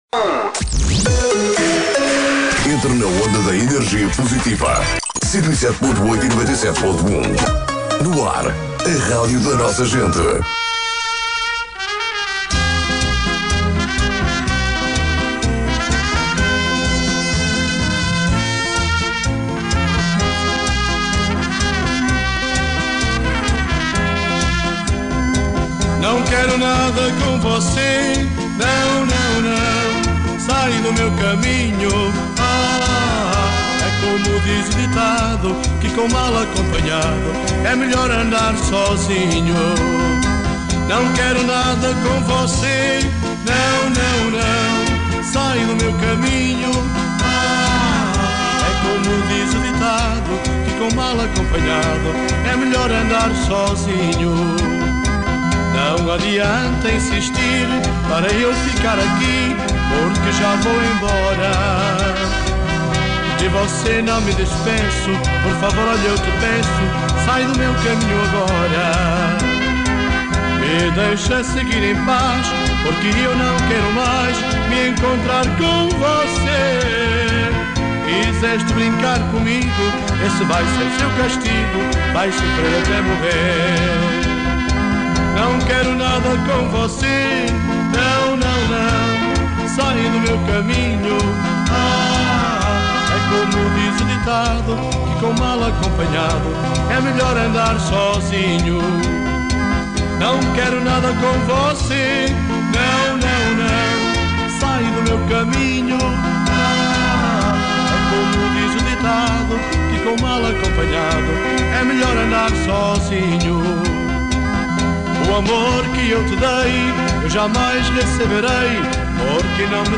Entrevista
Manhãs NoAr (direto)